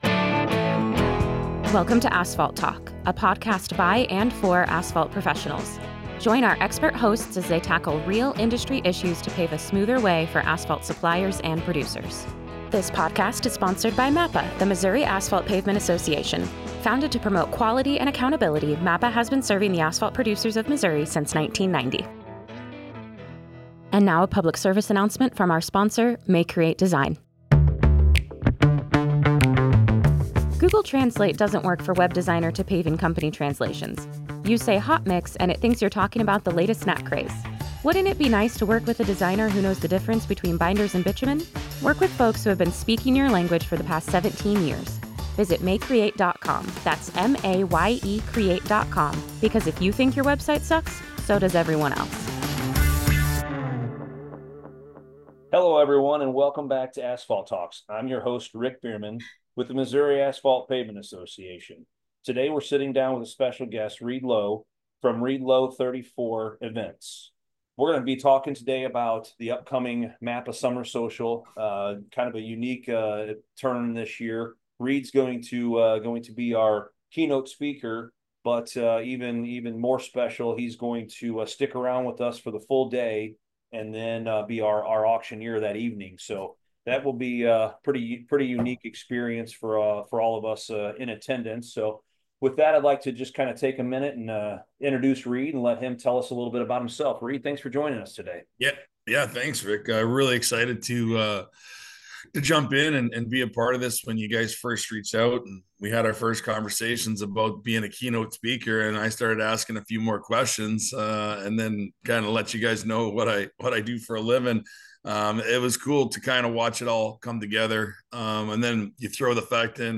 In this special episode of Asphalt Talks, we sit down with 2025 MAPA Summer Social keynote speaker and former St. Louis Blues enforcer, Reed Low. Reed gives us a preview of what attendees can expect from his keynote address — from his inspiring journey to the NHL, to the lessons he's learned in life after professional hockey.